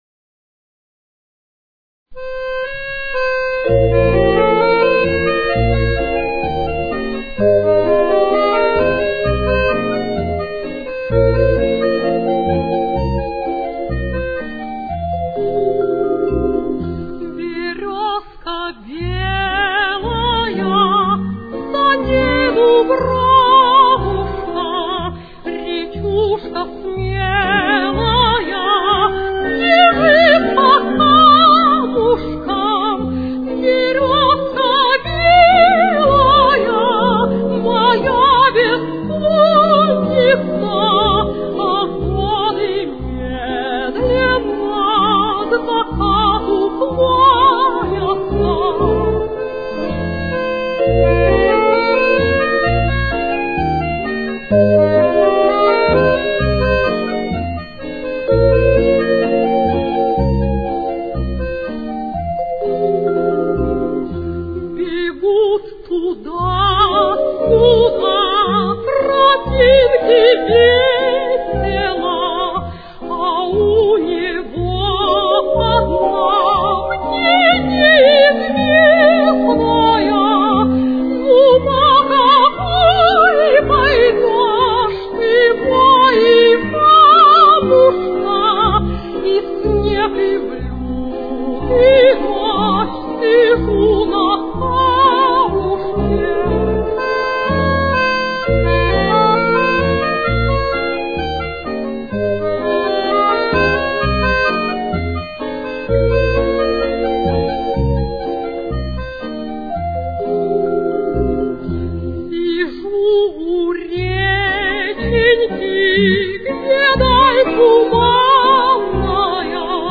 с очень низким качеством (16 – 32 кБит/с)
Фа минор. Темп: 62.